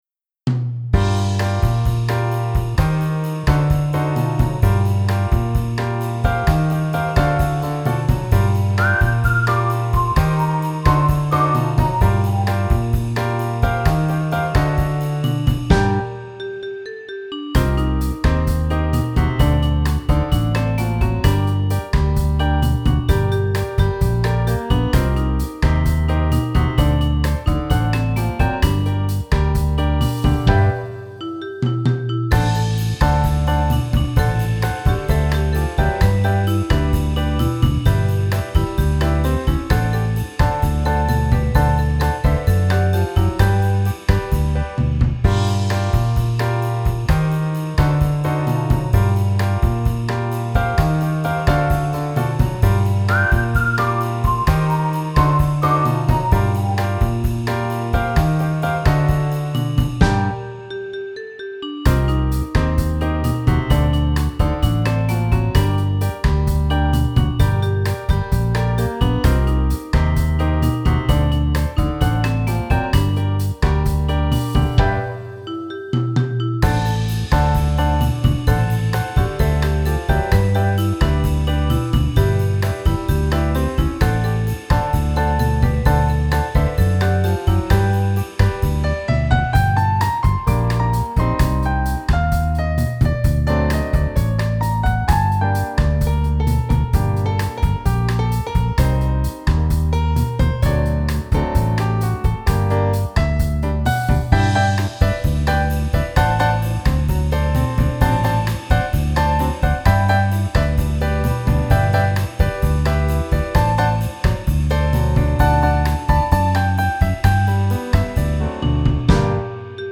3r-El-monstre-de-Banyoles-base-instrumental.mp3